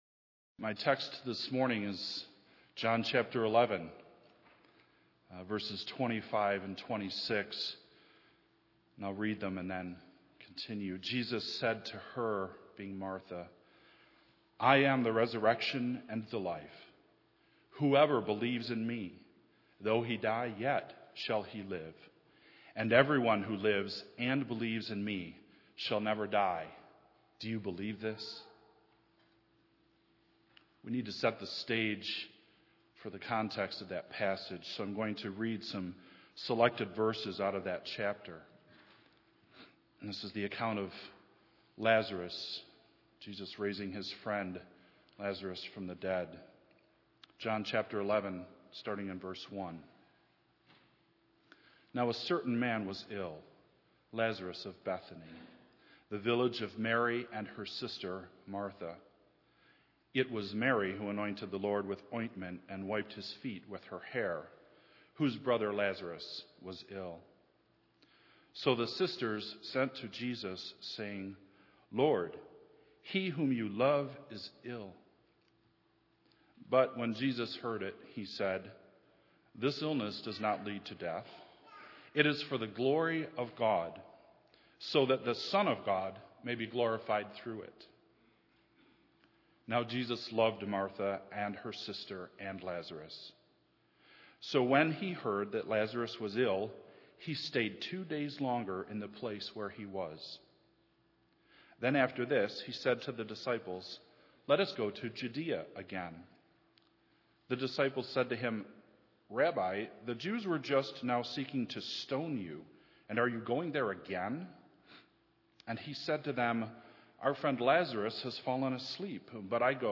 Easter 2012 Sunrise Service: White Haven Cemetery